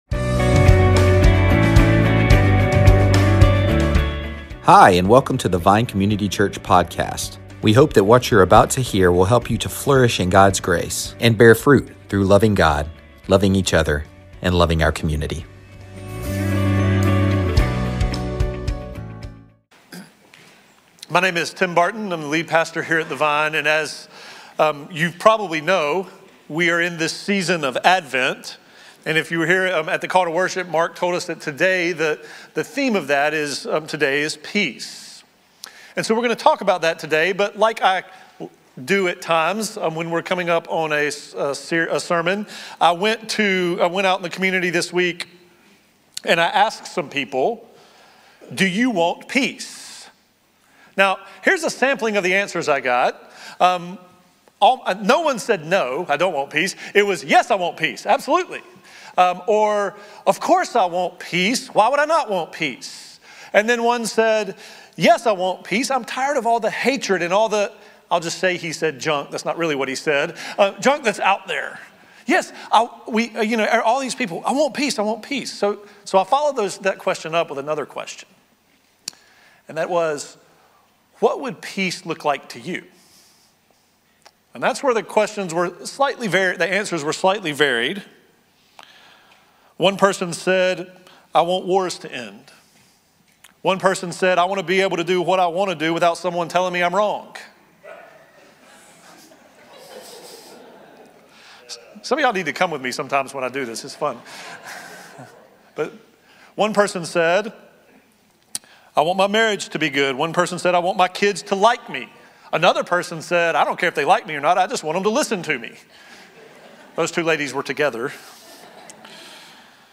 Sermons |
Christmas Eve